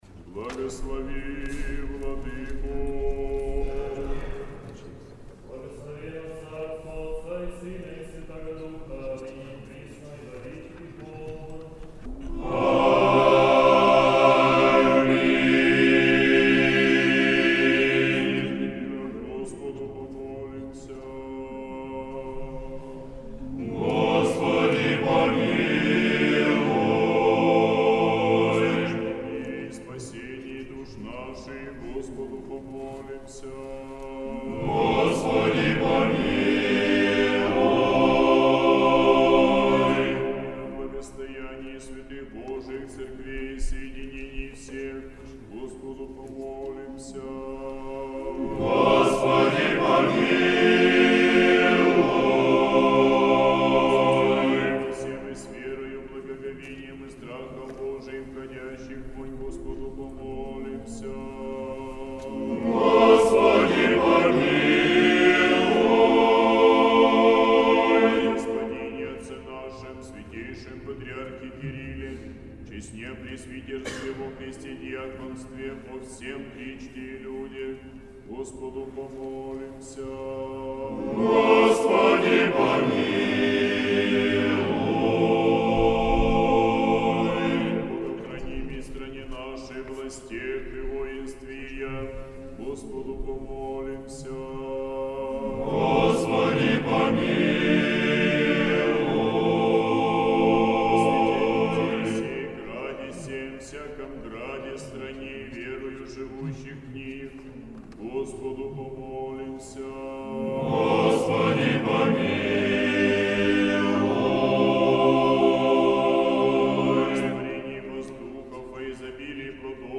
Сретенский монастырь. Утреня. Хор Сретенской Духовной Семинарии.